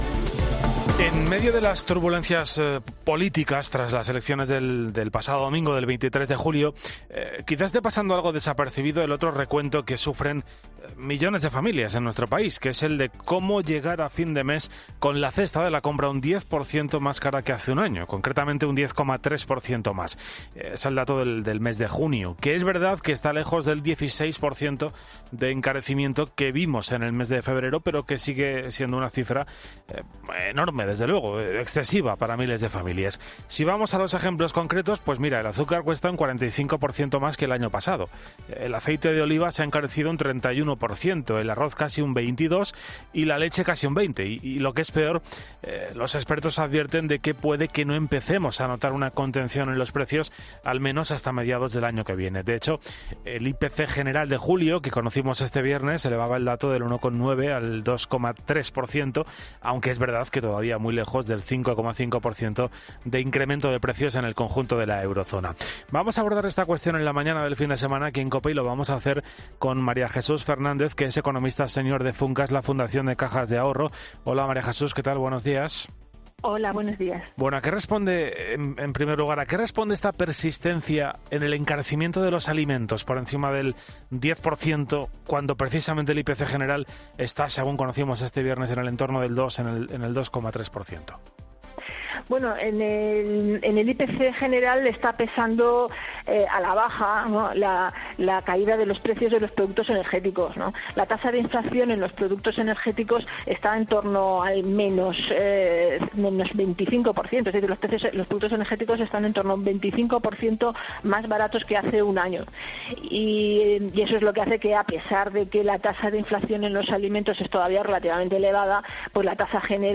Una economista explica por qué el precio de la cesta de la compra sigue subiendo mientras el IPC se modera